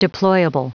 Prononciation du mot deployable en anglais (fichier audio)
Prononciation du mot : deployable